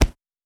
Ball Hit Leg.wav